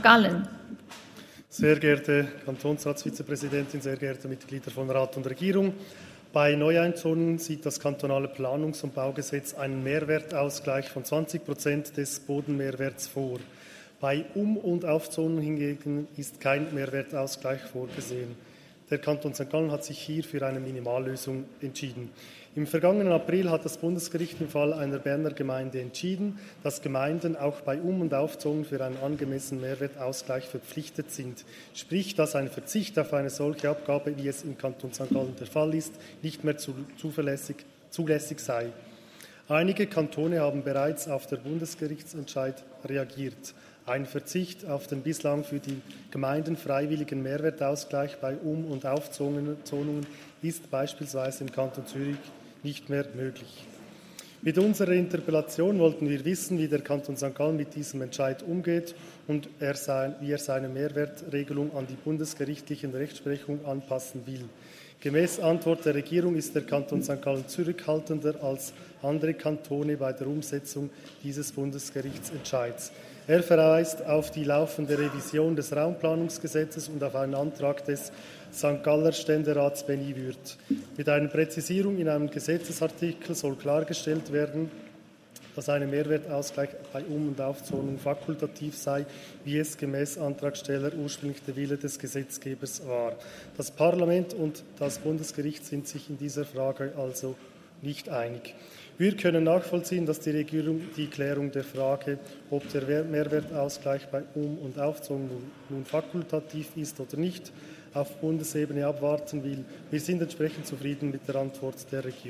13.2.2023Wortmeldung
Session des Kantonsrates vom 13. bis 15. Februar 2023, Frühjahrssession